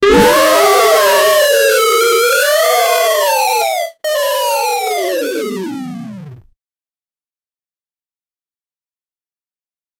bloated-8-year-old-girl-uos6dyqy.wav